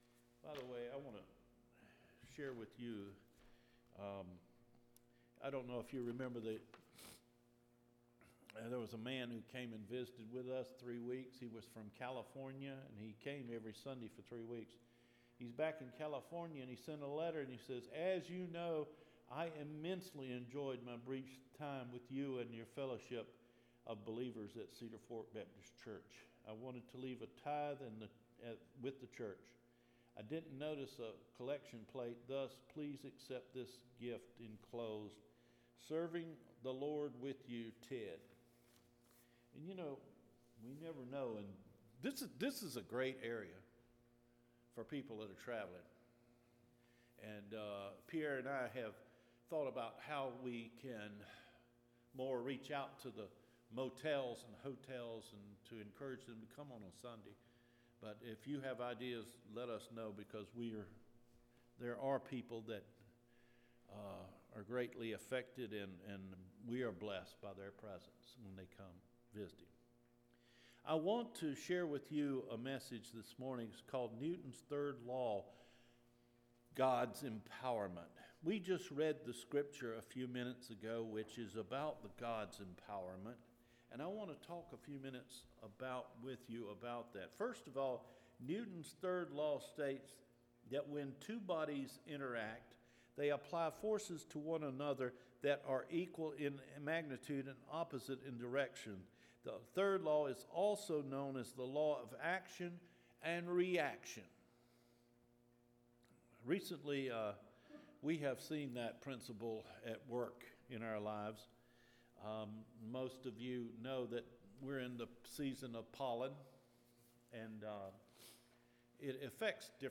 APRIL 18 SERMON – NEWTON’S 3RD LAW (GOD’S EMPOWERMENT)